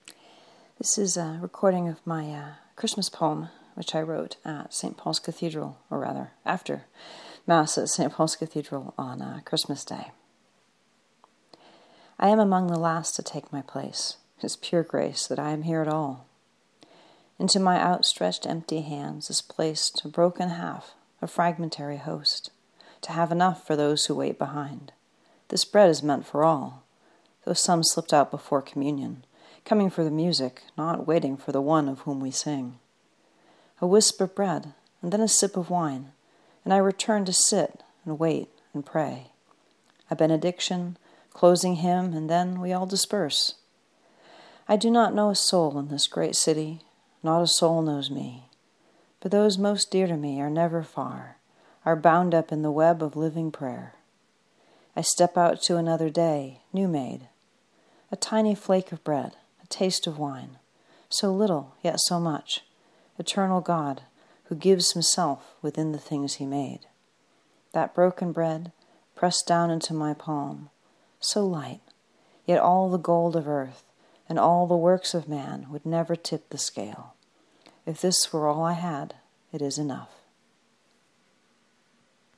Christmas day poem at St Paul's